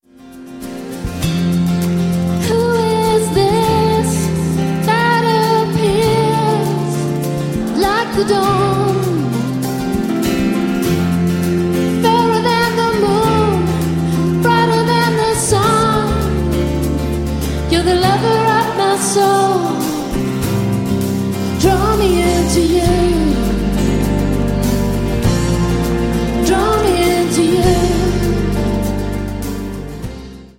Diese Live-CD entfacht ein kreatives
• Sachgebiet: Praise & Worship